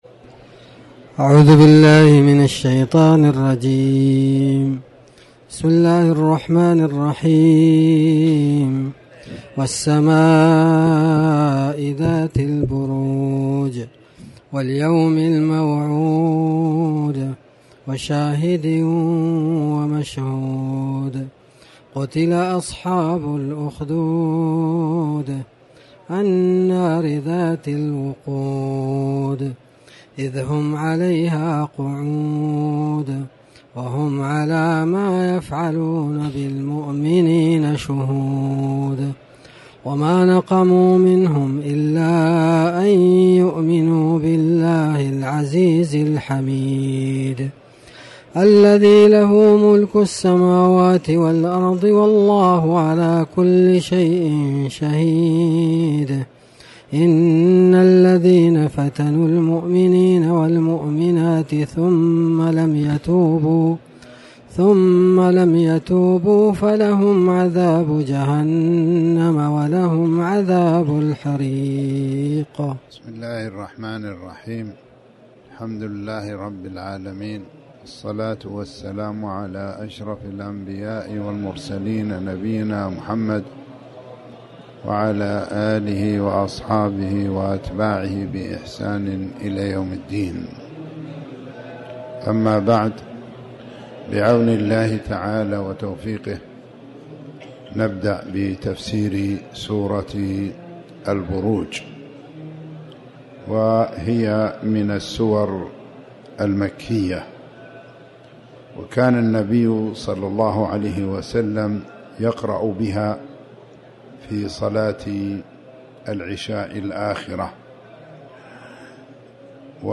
تاريخ النشر ٨ رمضان ١٤٤٠ هـ المكان: المسجد الحرام الشيخ